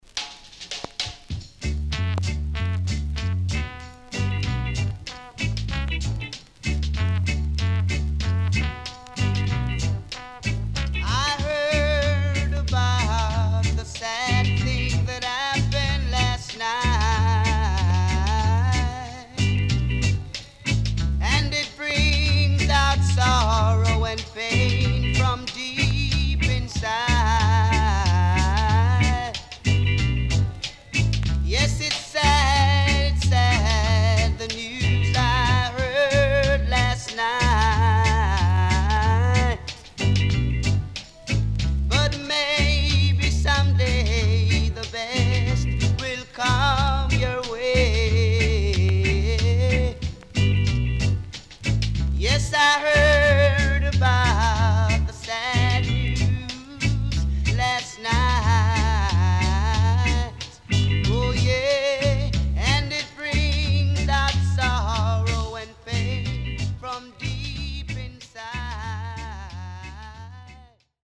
EARLY REGGAE
INST 70's
CONDITION SIDE A:VG+
SIDE A:少しチリノイズ入ります。